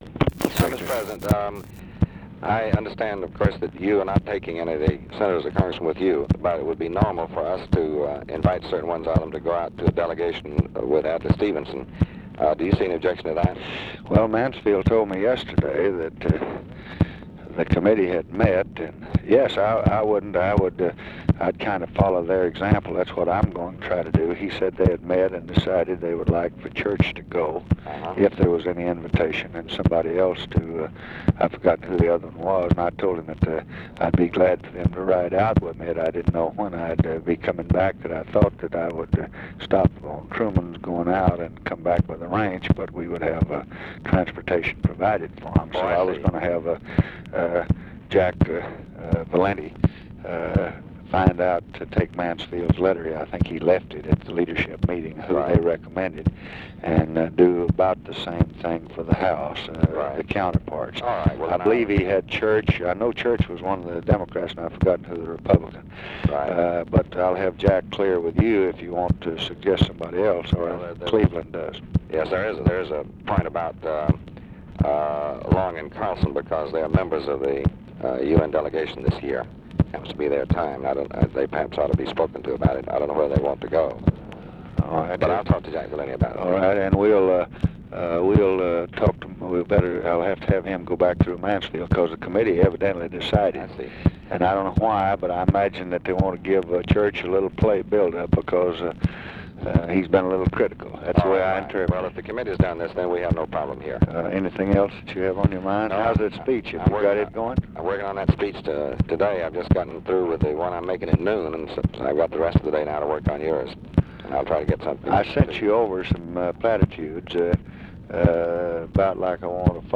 Conversation with DEAN RUSK, June 23, 1965
Secret White House Tapes